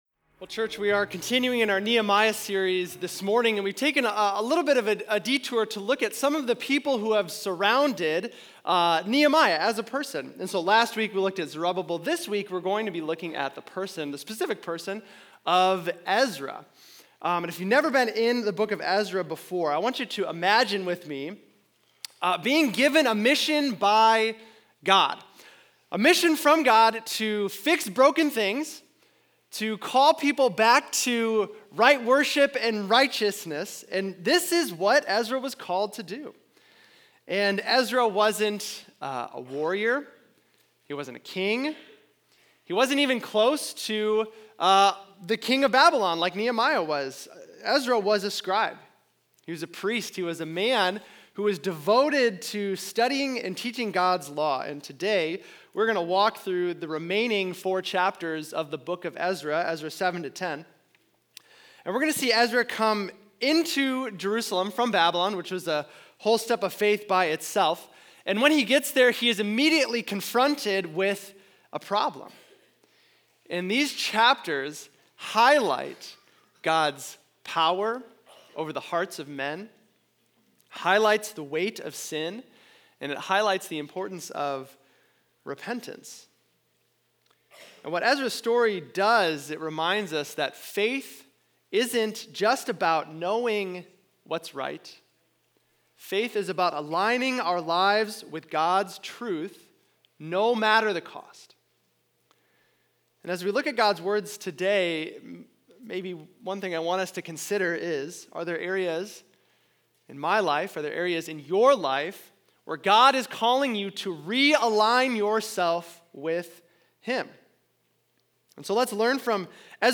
Ezra 7-10 | Ezra & Confession | Nehemiah - HP Campus Sermons